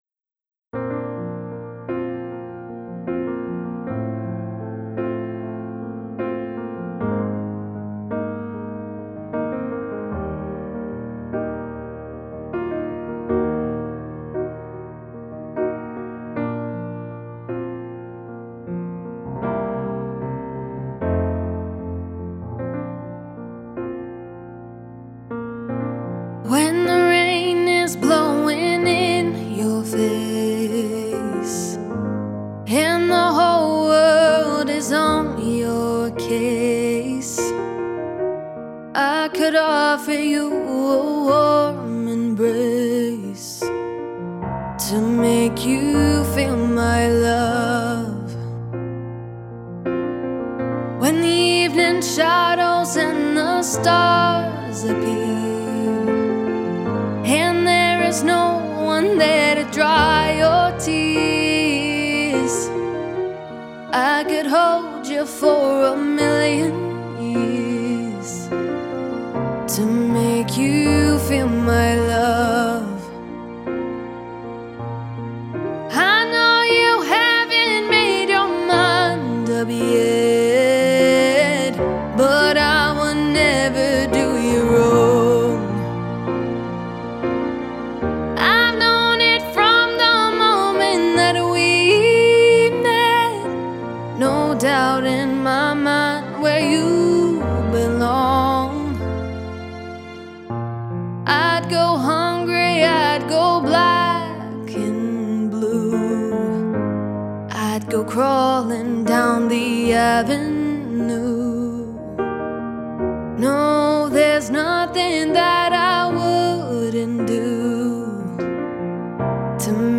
and I wanted to release a cover version of it.
such a classic and simple sound
piano
strings and woodwinds